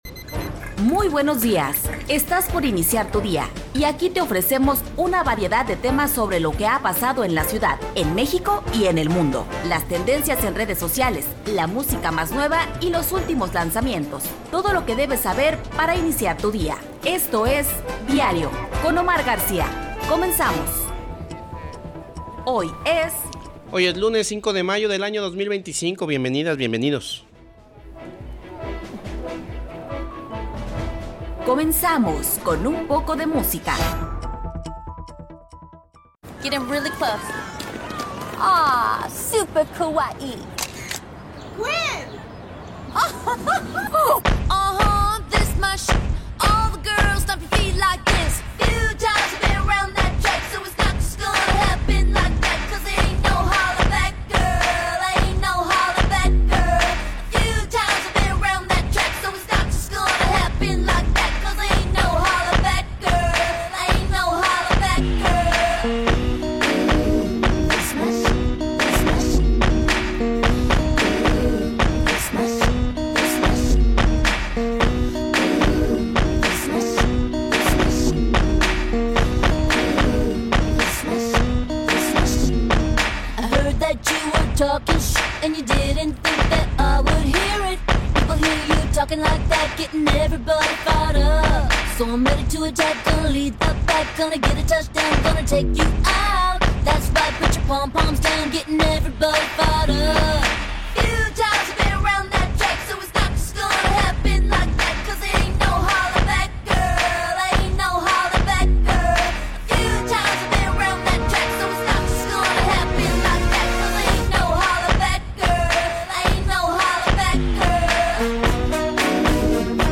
Hoy en Diario, Revista Informativa de Radio Universidad de Guadalajara